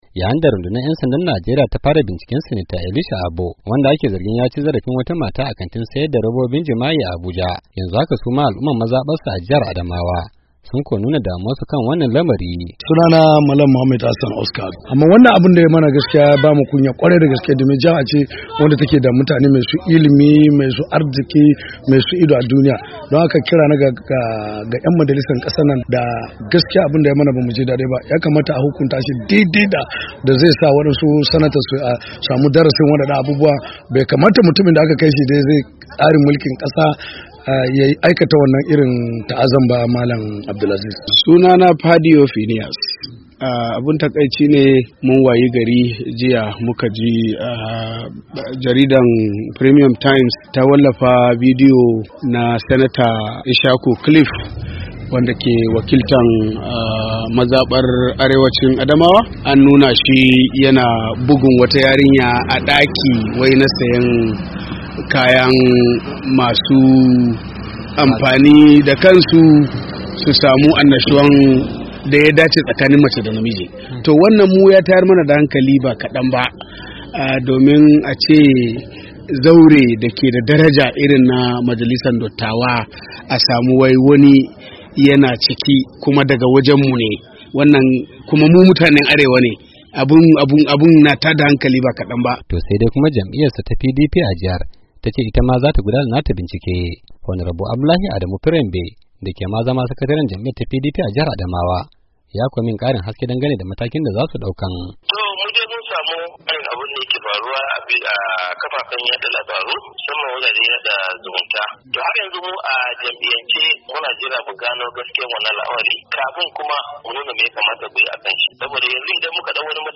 Ga cikakken rahotun wakilin Muryar Amurka